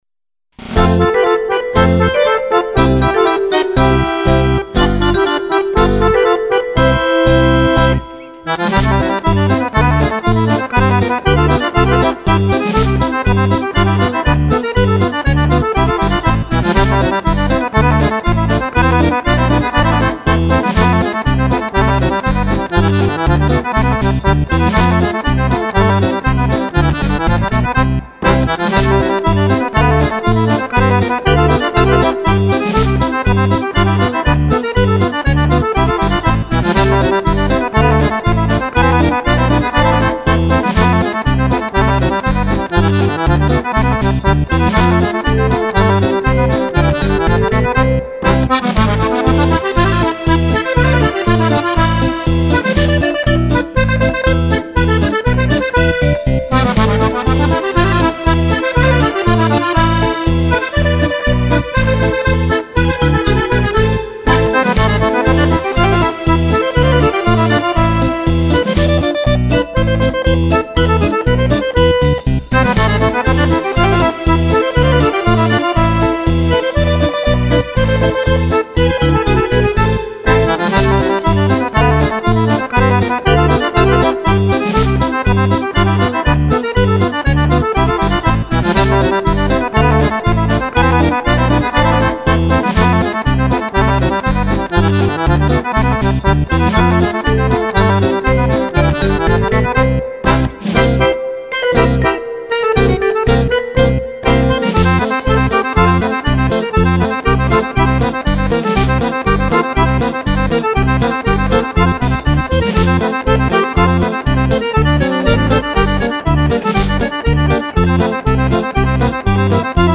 Polca per Fisarmonica